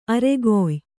♪ aregoy